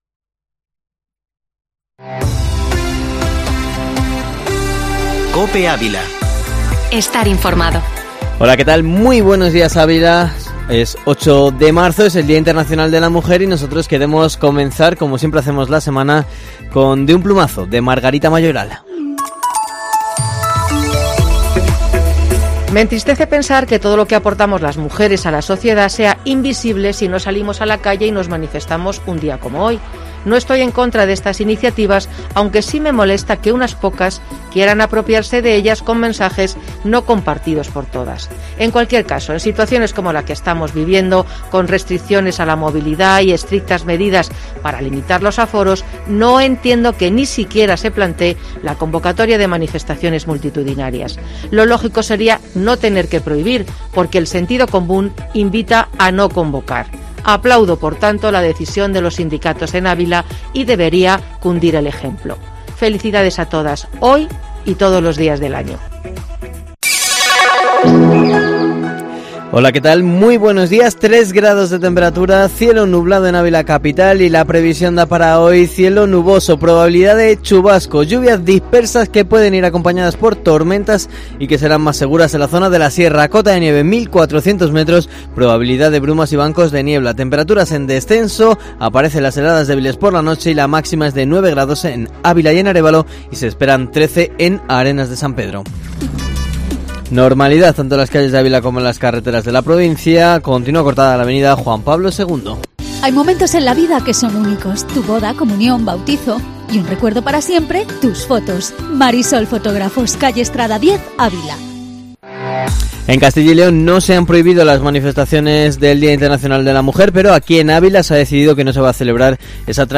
Informativo matinal Herrera en COPE Ávila 08/03/2021